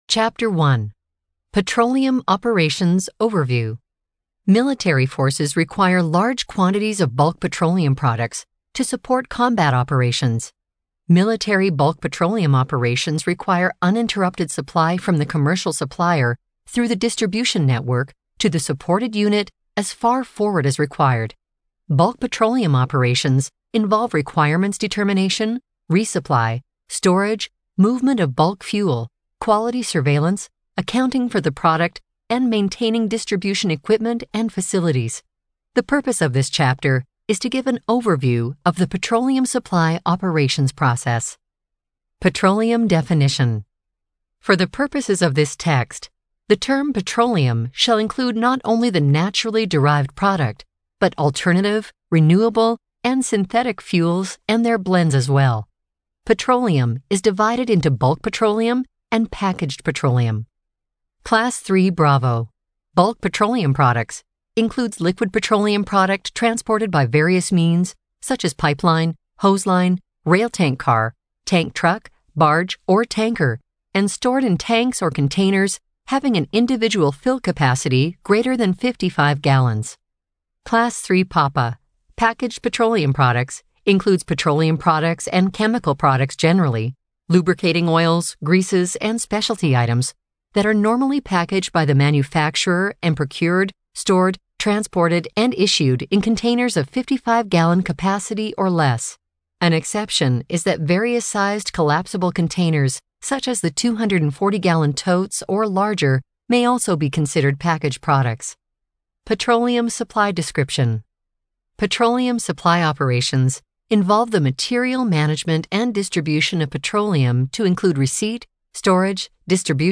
Army Doctrine Audiobook Download Page
It has been abridged to meet the requirements of the audiobook format.